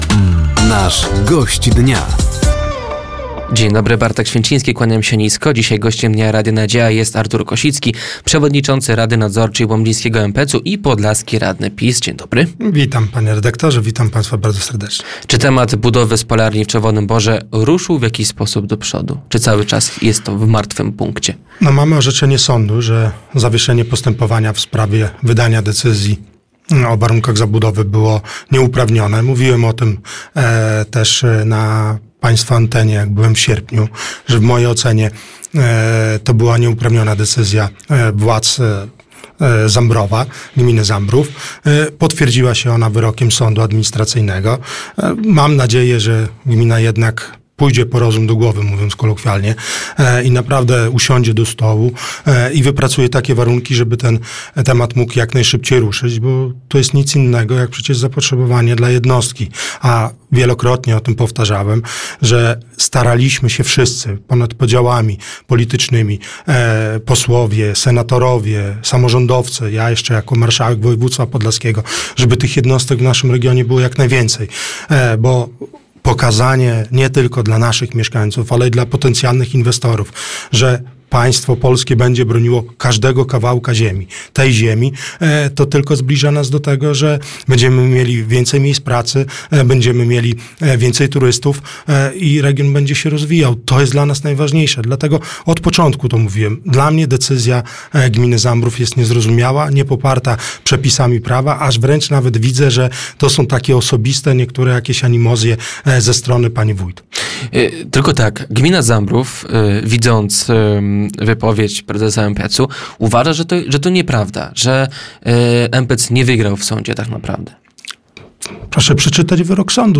Gościem Dnia Radia Nadzieja był przewodniczący rady nadzorczej łomżyńskiego MPEC-u i podlaski radny PiS Artur Kosicki. Tematem rozmowy była między innymi spalarnia w Czerwonym Borze, konsolidacja stacji pogotowia ze szpitalami i sytuacja PKS-u w Łomży.